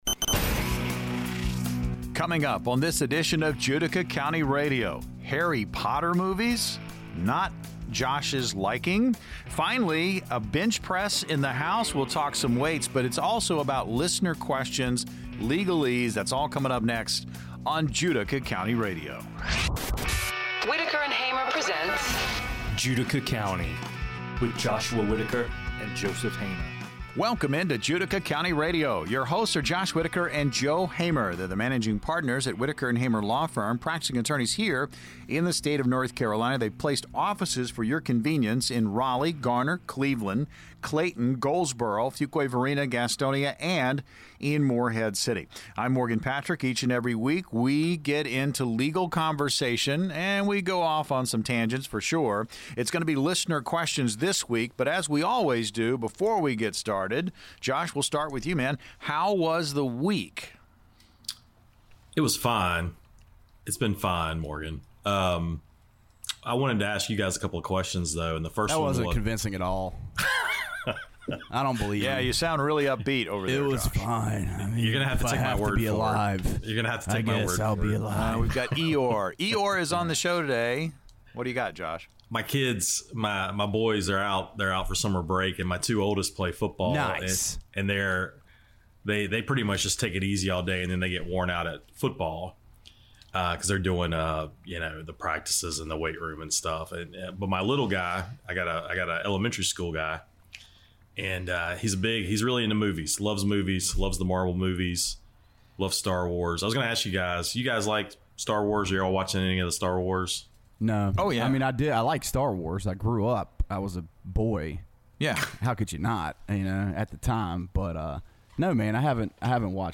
The conversation transitions into listener questions, focusing on legal issues such as life estates and estate planning, providing valuable insights and advice for listeners. In this episode, the hosts discuss various aspects of estate planning, including the responsibilities that come with inheriting property, the importance of having a solid estate plan, and the potential pitfalls of estate administration.